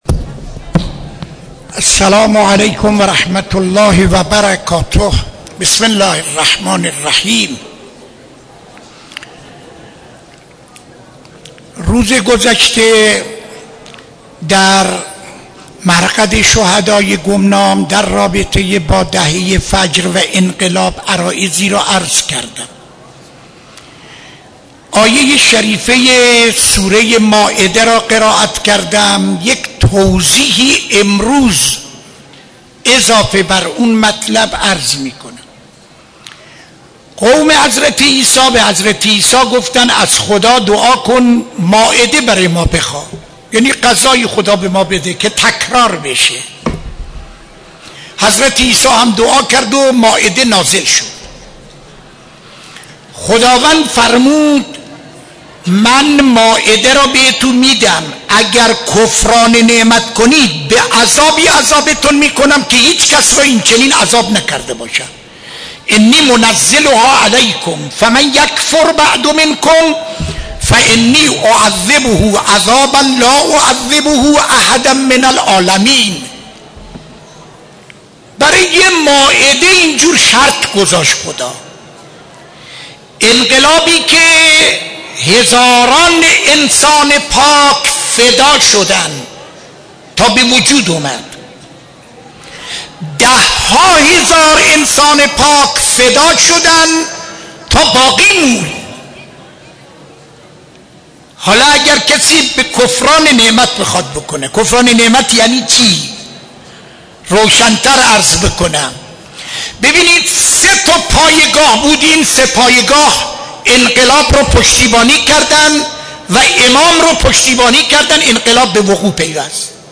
سخنرانی در مسجد دانشگاه (سه شنبه 13-11-94)